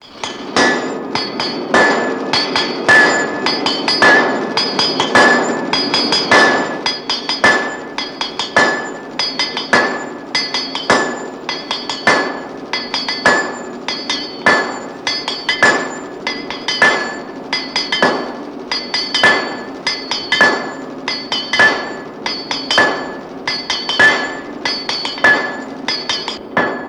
На этой странице собраны звуки кузницы и работы с наковальней: ритмичные удары молота, звон металла, фоновый гул мастерской.
Звуки кузницы где куют металлические изделия